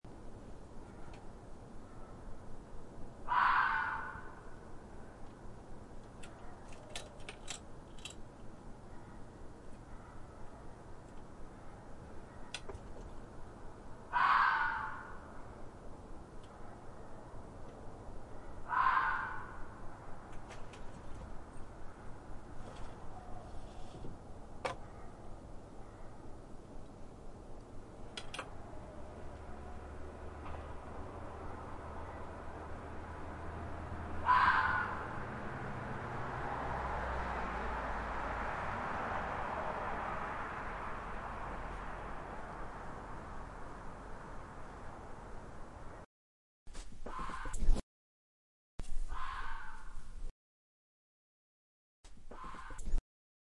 Sound Effects
Fox Scream